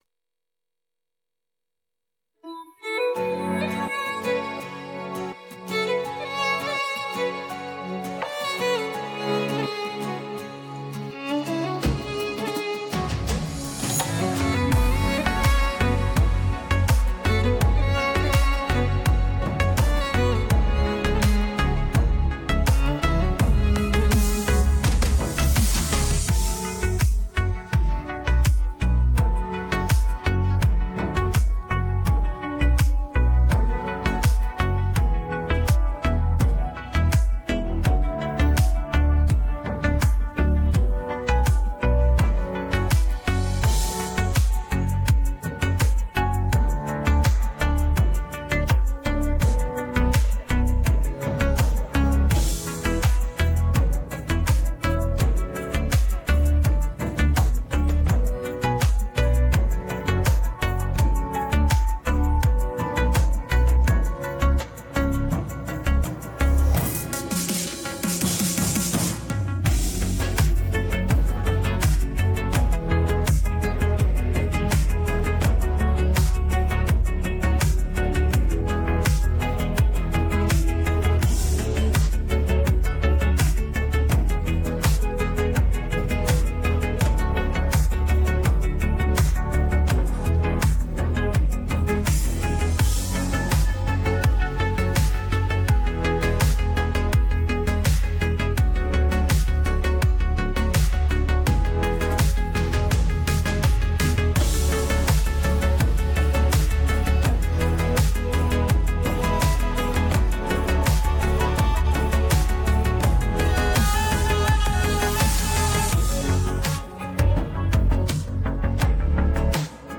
آهنگ بی‌کلام